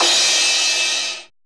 RX CRASH.wav